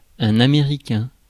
Ääntäminen
Synonyymit anglais américain (harvinainen) étasunien (harvinainen) étatsunien (harvinainen) états-unien mitraillette usanien Ääntäminen France: IPA: [ɛ̃n‿a.me.ʁi.kɛ̃] Tuntematon aksentti: IPA: /a.me.ʁi.kɛ̃/